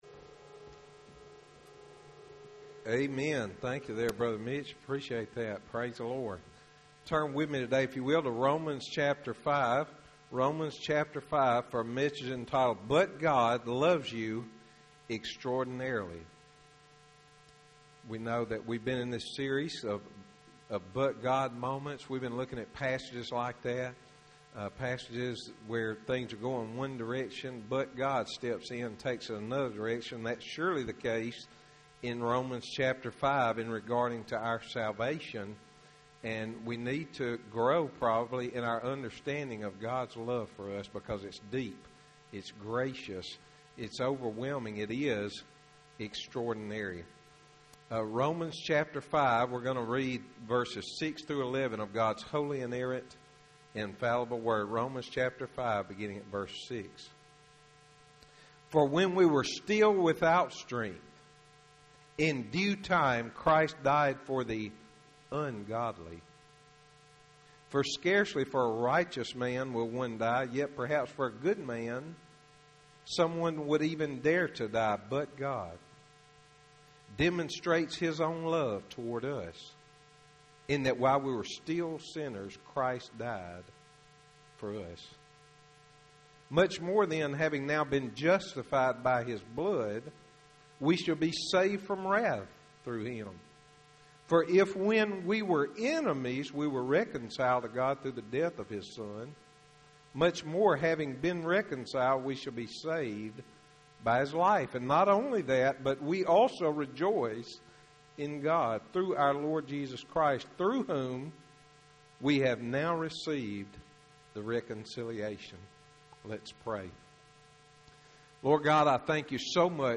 Sermons - Highland Baptist Church